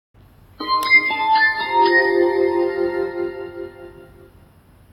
小奥关机提示音.MP3